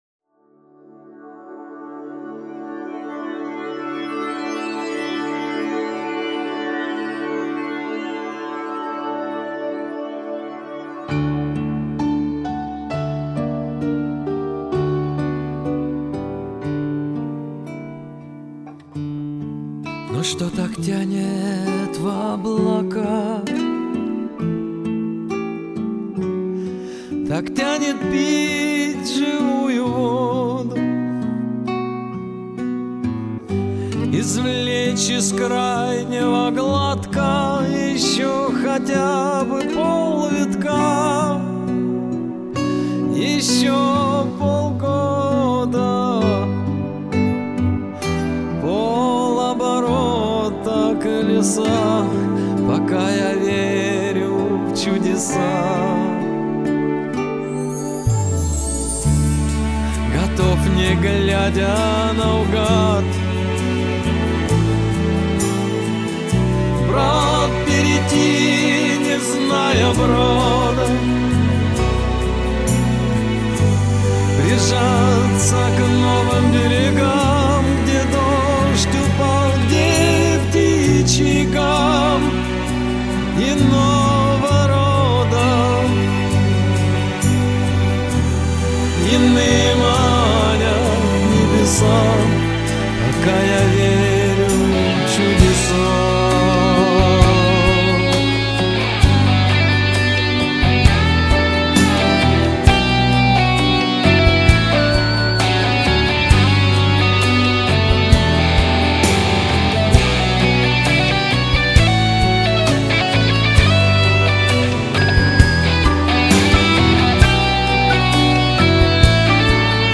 Группа Нероли.